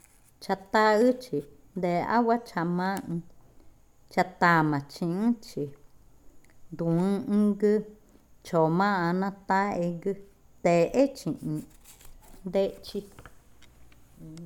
Adivinanza 6. El paiche
Cushillococha